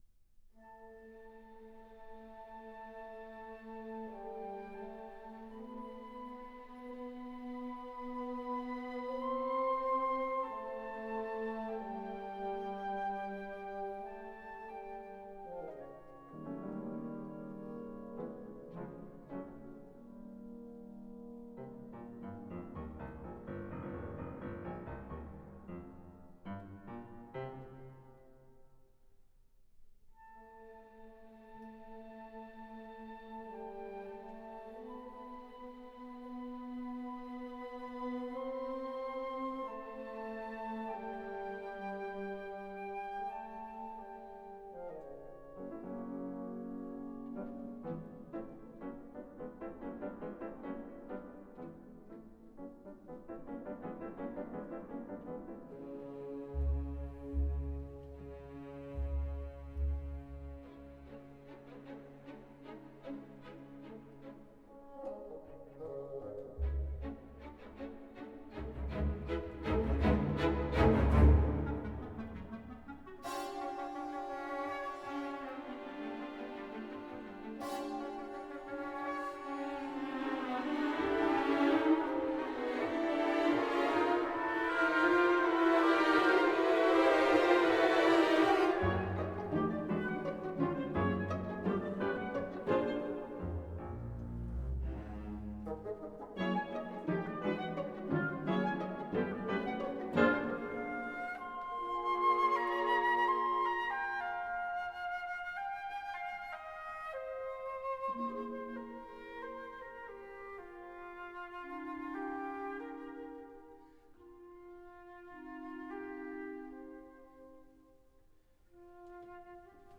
Jouée par l’orchestre de l’ONPL et animée par la compagnie Atipik.